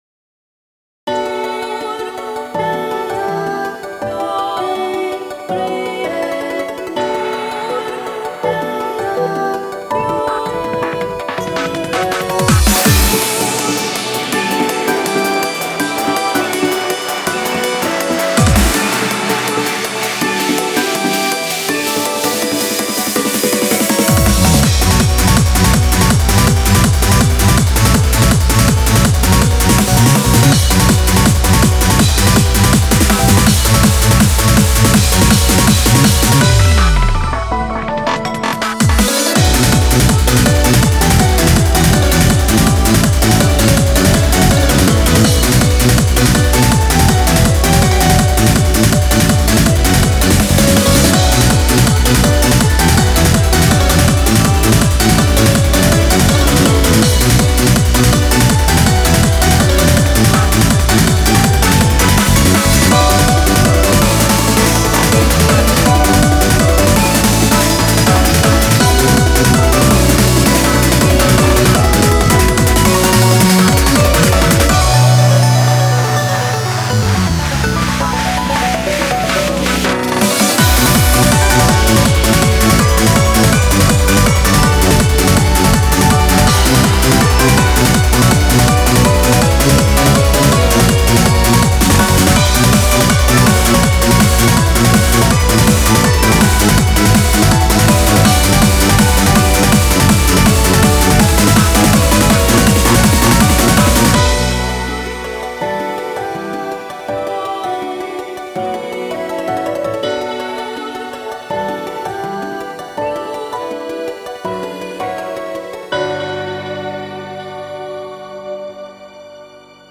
BPM82-163
Audio QualityPerfect (High Quality)
EPIC ELECTRONICA.